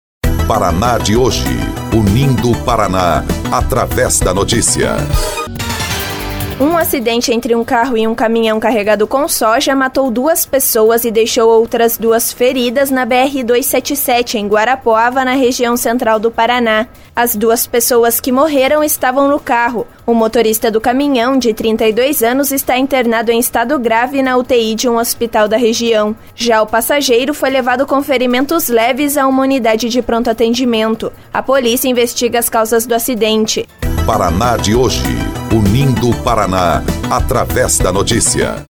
BOLETIM – Acidente entre carro e caminhão deixa dois mortos e dois feridos na BR-277